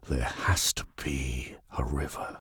B_river3.ogg